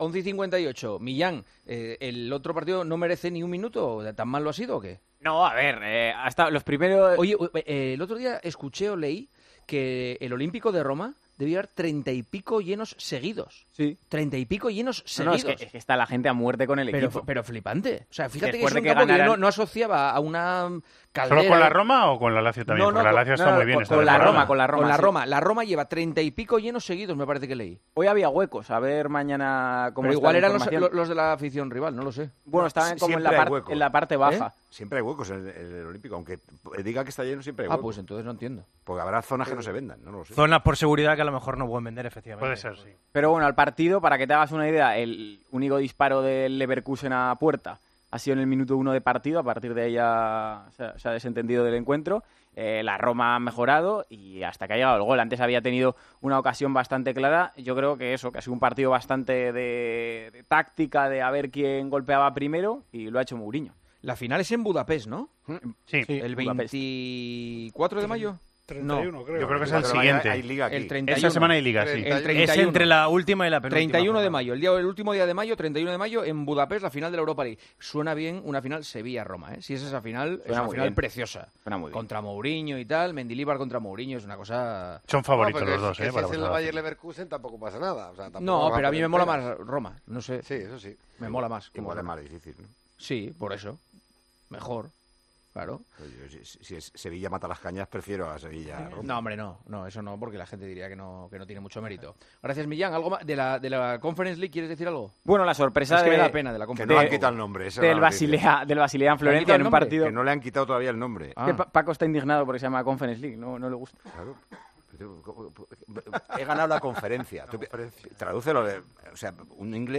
El director de Tiempo de Juego mantiene su guerra cruzada contra la denominación de la tercera competición europea, como trató de explicar este jueves con Juanma Castaño.